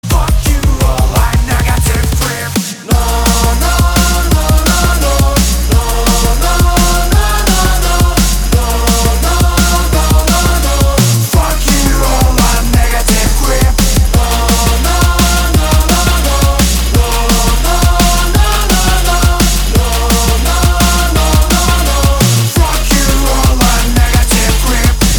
• Качество: 320, Stereo
громкие
Electronic
drum n bass
breakbeat